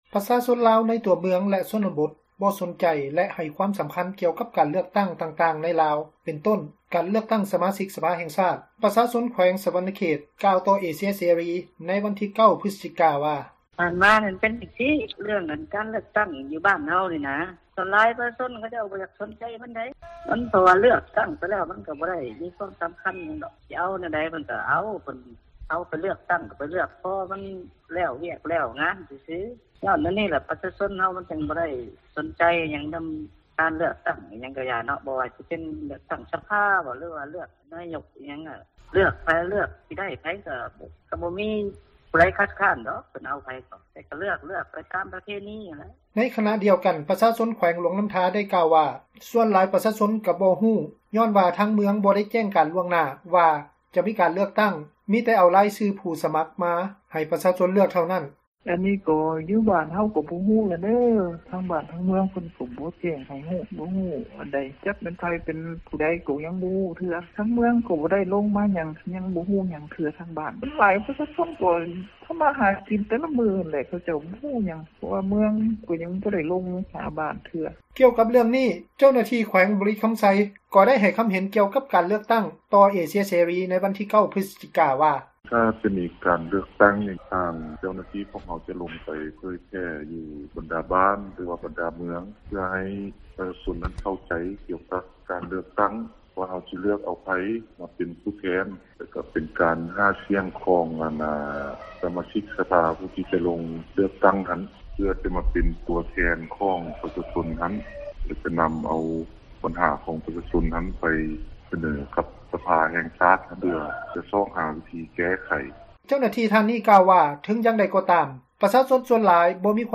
ປະຊາຊົນລາວ ຢູ່ໃນຕົວເມືອງ ແລະ ຊົນນະບົດ ບໍ່ສົນໃຈ ແລະ ໃຫ້ຄວາມສຳຄັນໜ້ອຍ ກ່ຽວກັບ ການເລືອກຕັ້ງຕ່າງໆ ໃນລາວ ເປັນຕົ້ນ ການເລືອກຕັ້ງເອົາ ສະມາຊິກສະພາ. ປະຊາຊົນ ຢູ່ແຂວງ ສວັນນະເຂດ ກ່າວຕໍ່ ເອເຊັຽເສຣີ ໃນມື້ວັນທີ 9 ພຶສຈິກາ ວ່າ:
ກ່ຽວກັບເຣື່ອງນີ້, ເຈົ້າໜ້າທີ່ ແຂວງບໍຣິຄຳໄຊ ກໍໄດ້ໃຫ້ຄຳເຫັນ ກ່ຽວກັບ ການເລືອກຕັ້ງ ຕໍ່ເອເຊັຽເສຣີ ໃນວັນທີ 9 ພຶສຈິກາ ວ່າ: